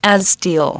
per-as steel_02.wav